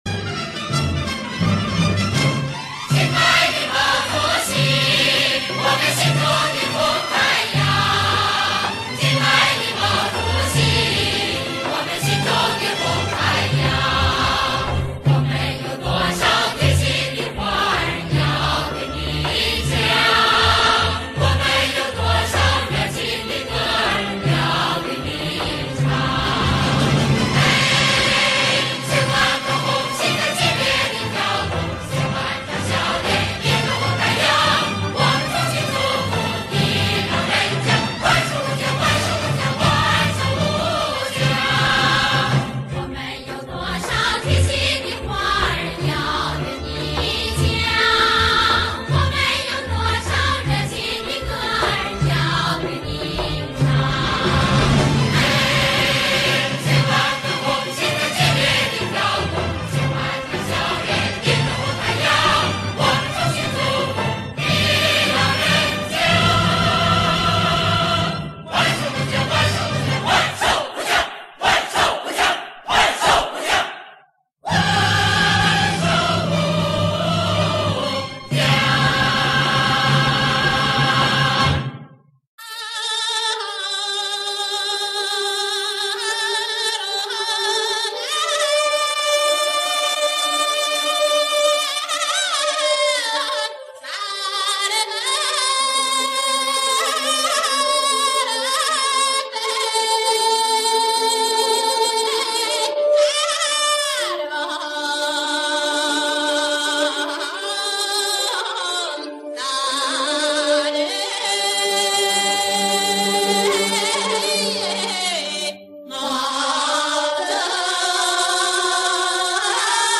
[21/9/2020]经典红歌《祝毛主席万寿无疆》二首早期演唱版（劫夫曲 + 阿拉腾奥勒曲）